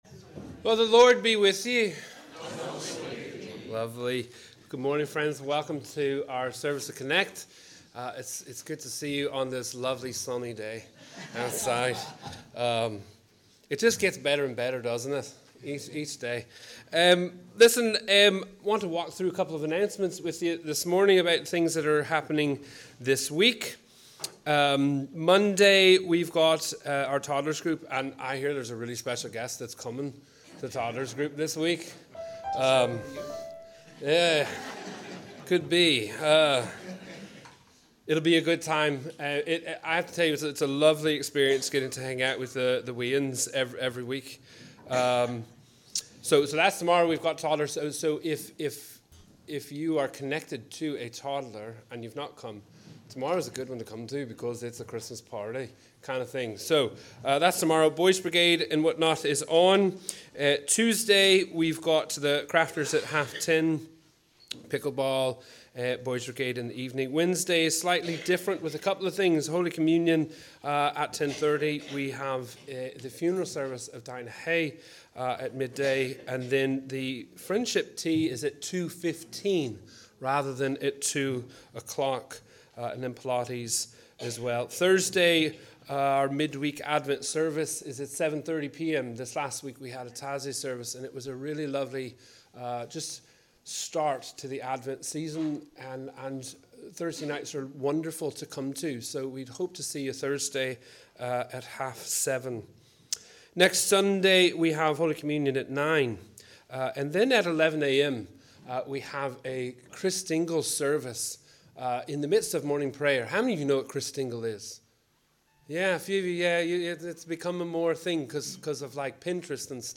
Wherever you are, we warmly welcome you to our Connect service on this second Sunday of Advent. Our theme is ‘worship.’